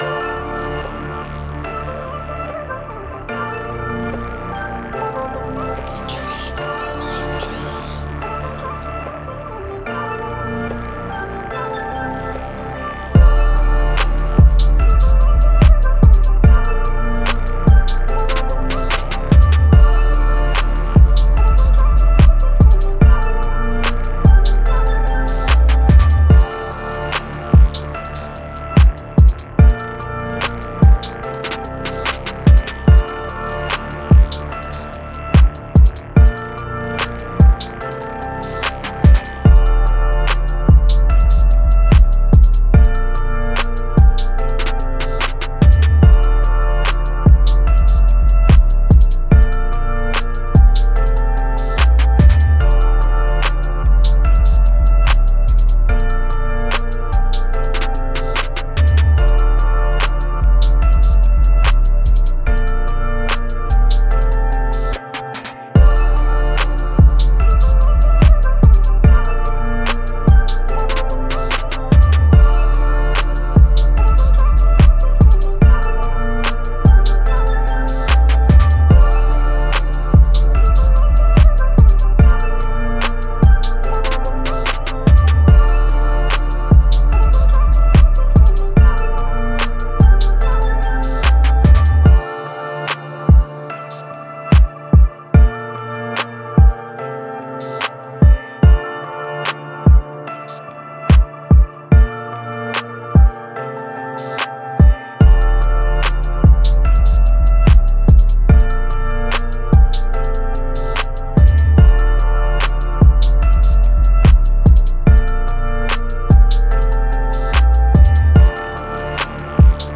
Moods: hard, intense, dramatic
Genre: Rap
Tempo: 146
hard, intense, dramatic type beat.